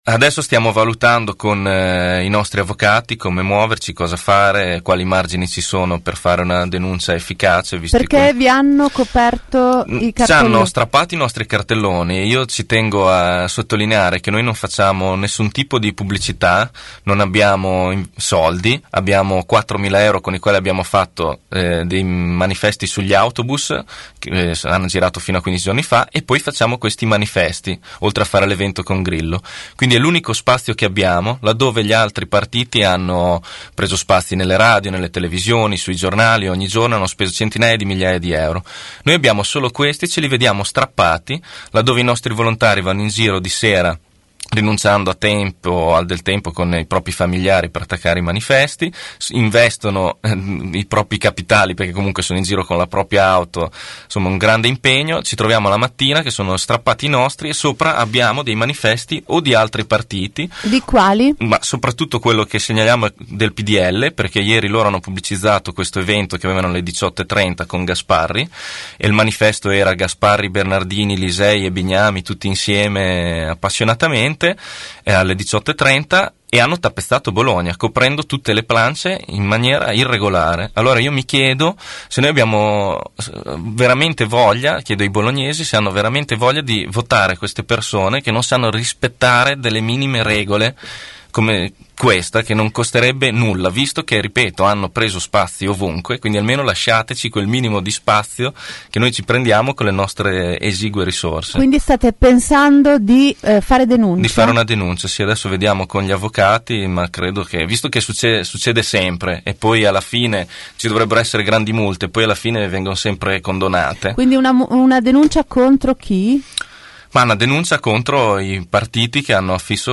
ospite dei nostri studi